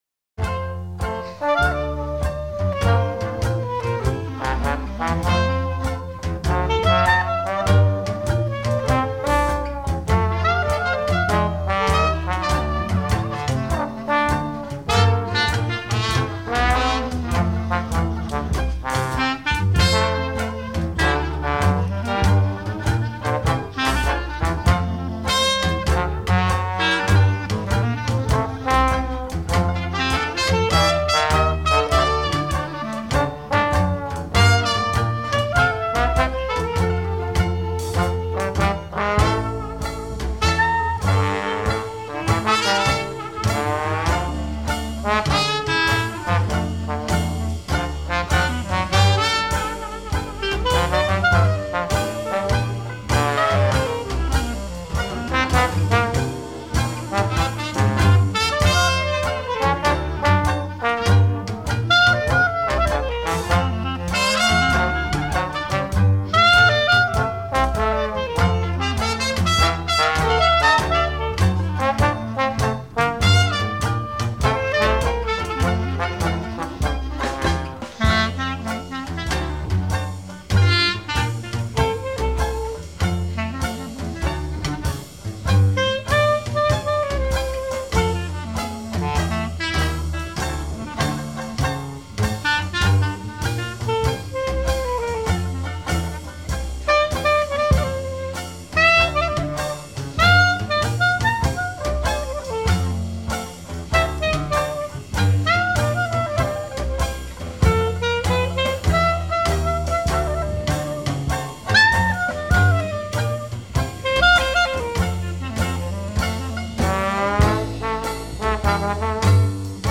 Traditional old-school New Orleans Dixieland jazz band
Completely mobile and unamplified